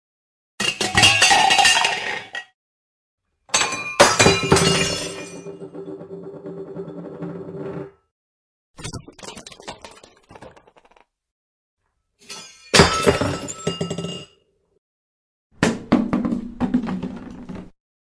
对象崩溃
描述：不同的家用物品被投掷或掉落在坚硬的地板上，并且使用智能手机捕获声音。
标签： 下降 家庭 对象 打破 地板 防撞
声道立体声